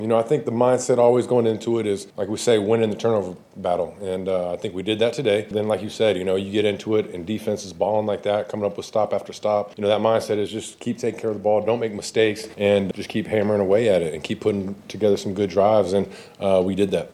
Packers quarterback Jordan Love said the way the defense was playing, they knew they didn’t have to take as many chances.